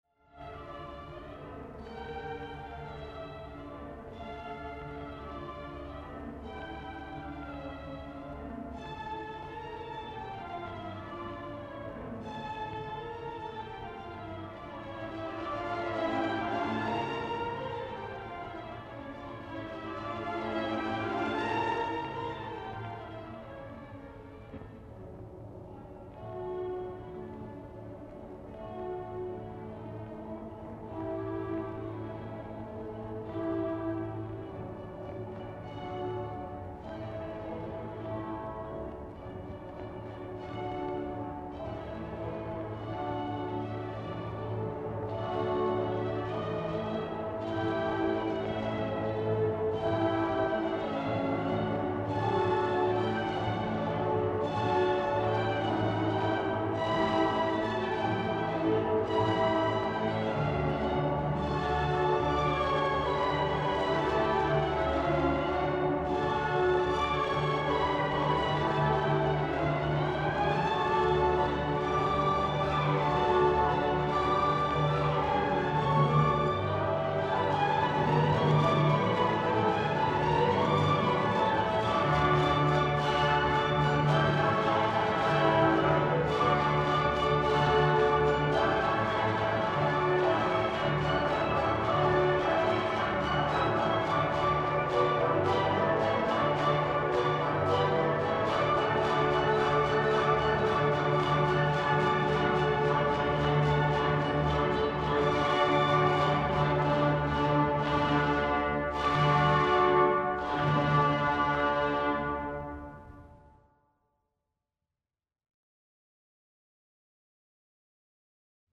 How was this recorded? (MONO)